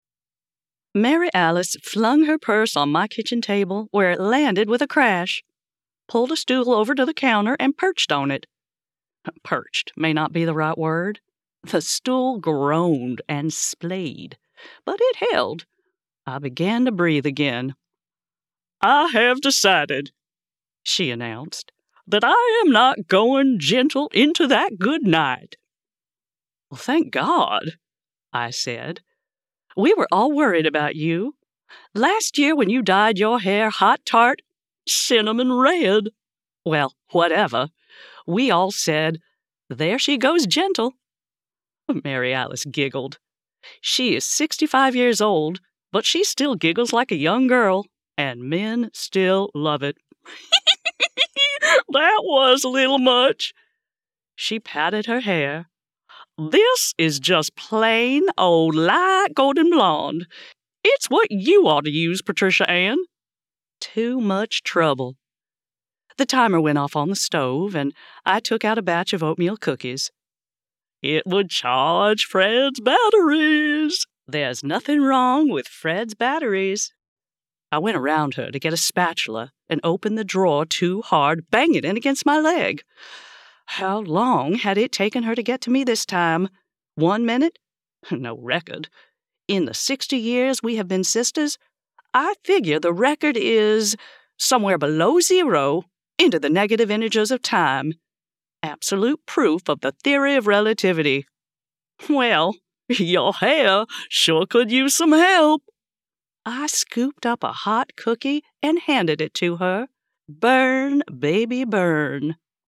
Fiction Humor | Southern accents | Middle-aged sisters in the kitchen
Professional Home Studio
- Professional Sound Treatment